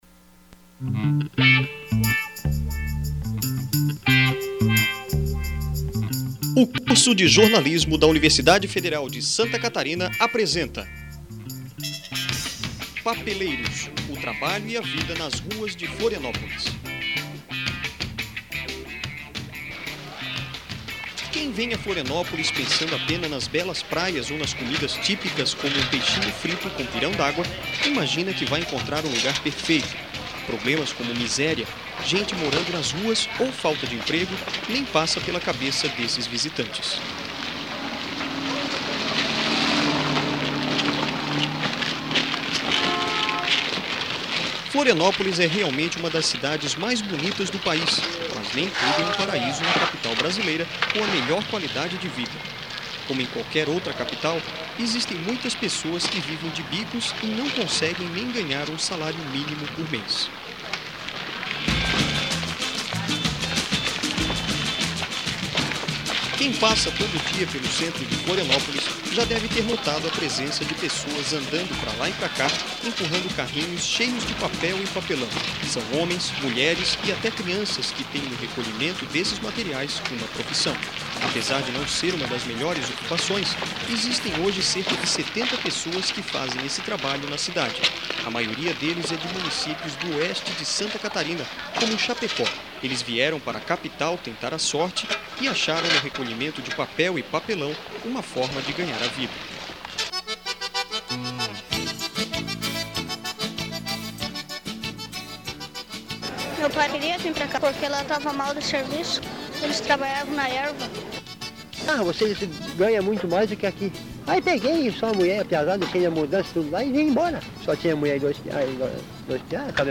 Documentário